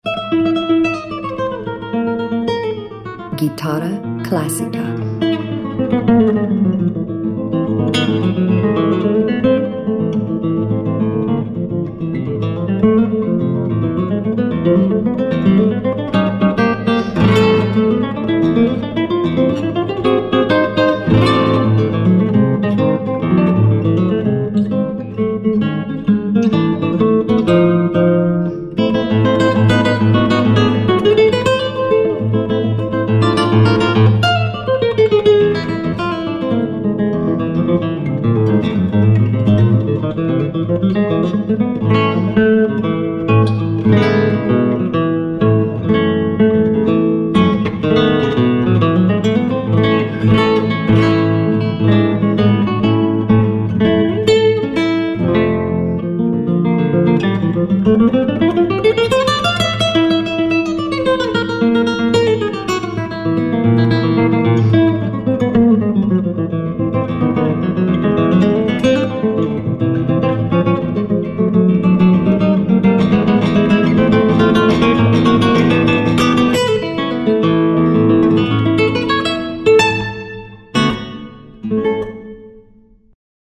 latinských rytmů klasické kytary
Relaxace, Meditace, Relaxační a Meditační hudba
Verbální vedení: Neverbální